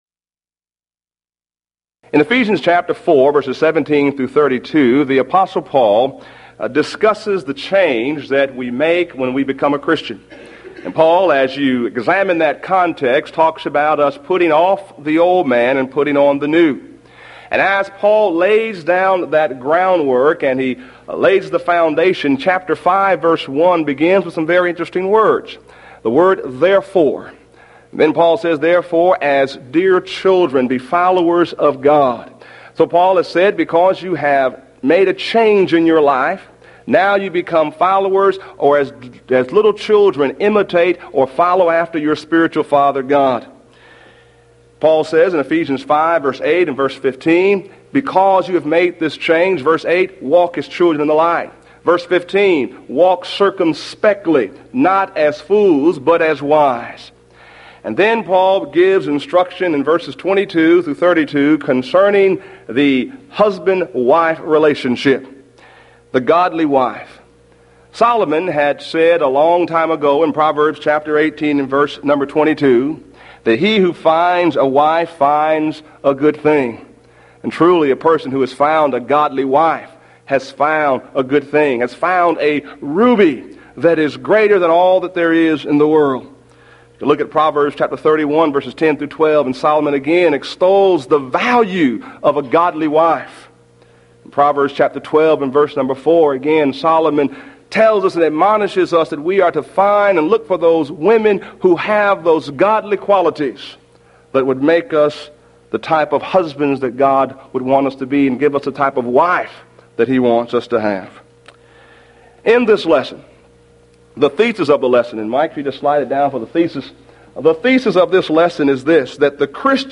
Event: 1993 Mid-West Lectures